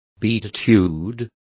Complete with pronunciation of the translation of beatitude.